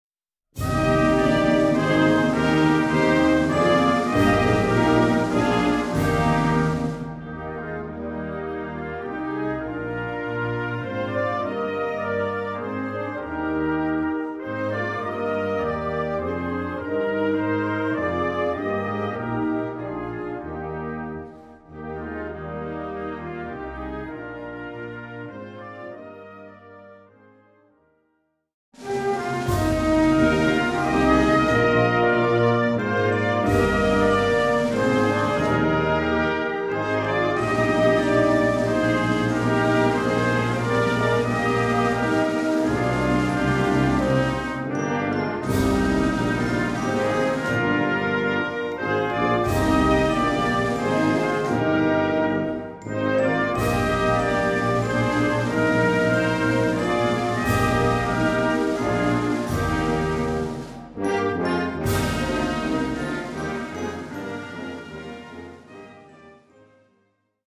Concert Band ou Harmonie ou Fanfare